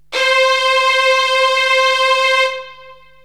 STRINGS 0005.wav